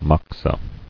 [mox·a]